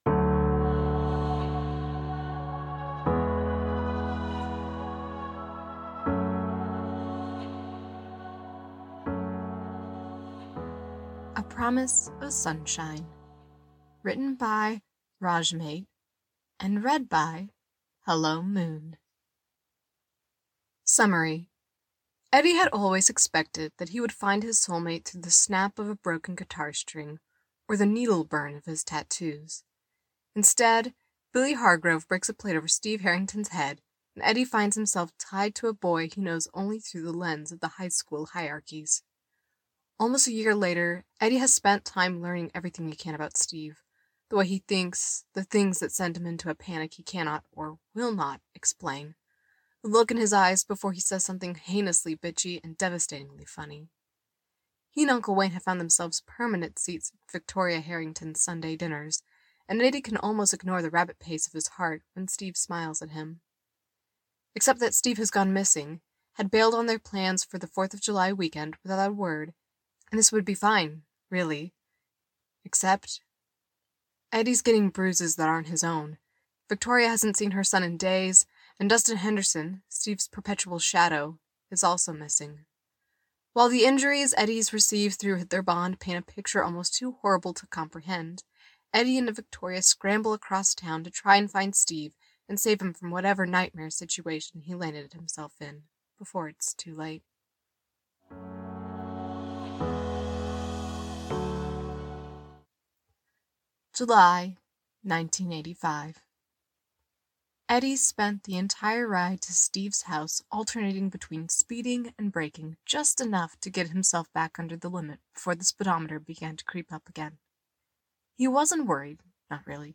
with SFX download mp3: here (r-click or press, and 'save link') [86 MB, 02:01:56]